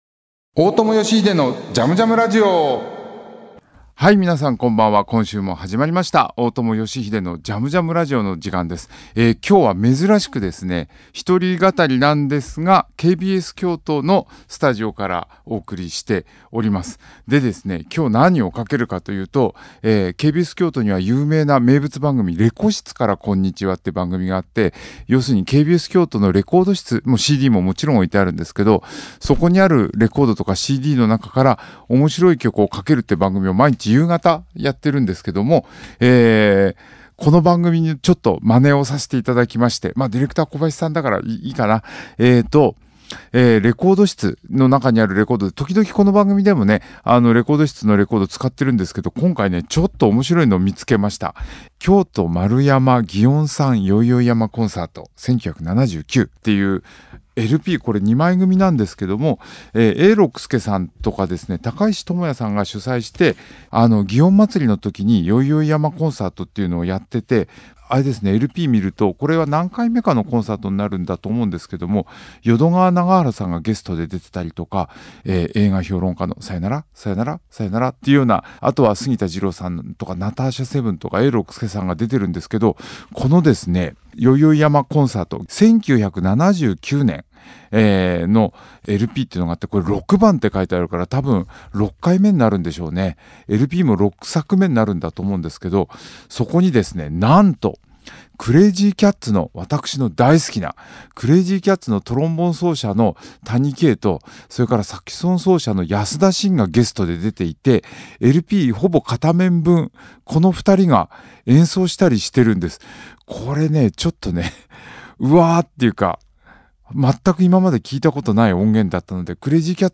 音楽家・大友良英がここでしか聞けないような変わった音楽から昔懐かしい音楽に至るまでのいろんな音楽とゲストを招いてのおしゃべりや、リスナーの皆さんからのリクエストやメッセージにもお答えしていくこの番組ならではのオリジナルなラジオ番組です。
この中から、今夜は谷啓、安田伸のパフォーマンスをお聞きいただきました。